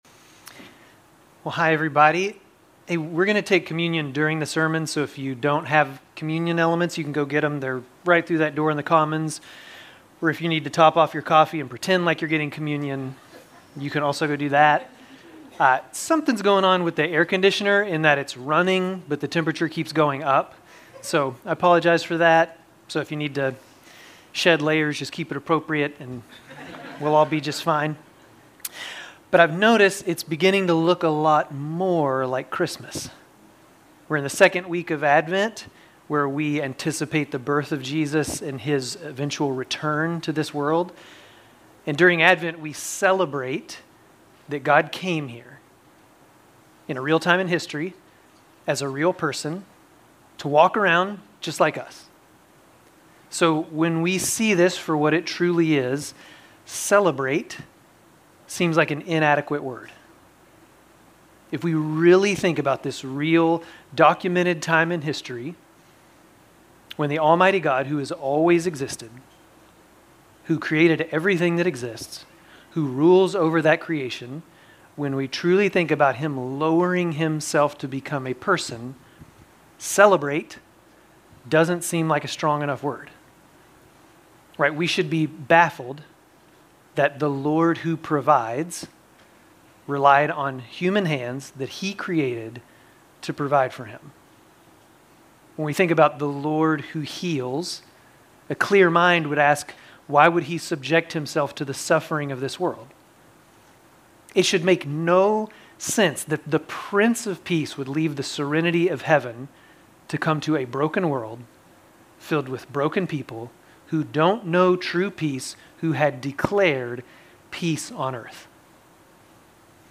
Grace Community Church Dover Campus Sermons 12_7 Dover Campus Dec 08 2025 | 00:28:26 1x 00:00 / 00:28:26 Subscribe Share RSS Feed Share Link Embed